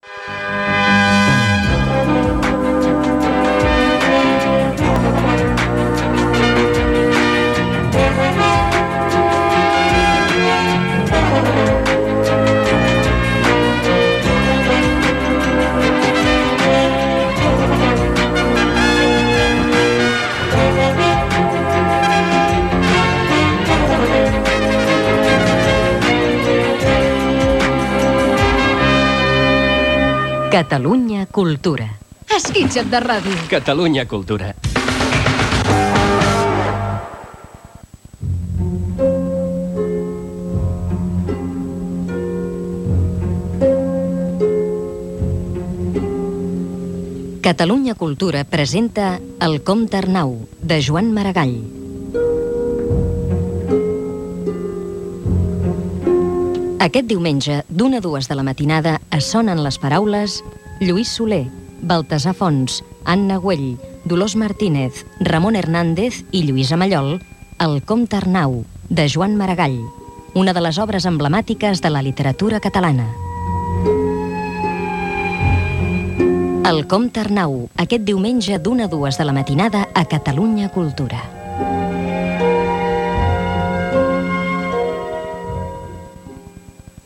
Indicatiu de l'emissora i promo de la versió radiofònica de l'obra "El comte Arnau" del programa "Sonen les paraules".